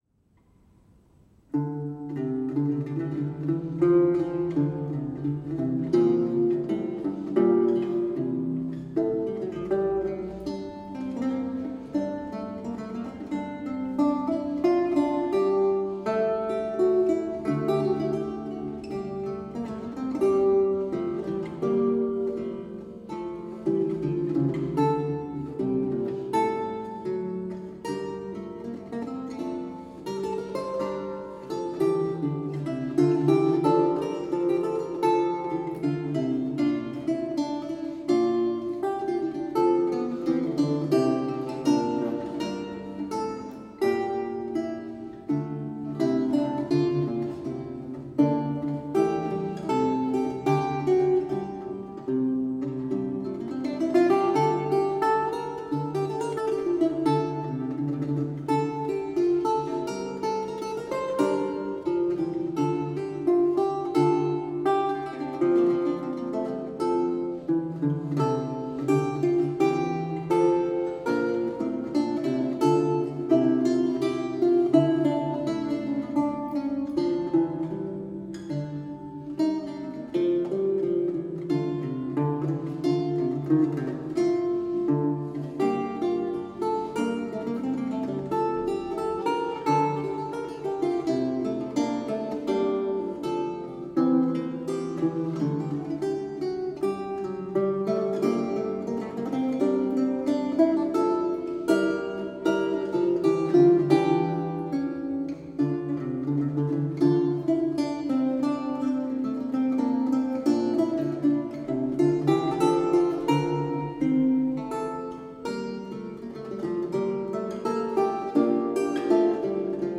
lute
Audio recording of a lute piece from the E-LAUTE project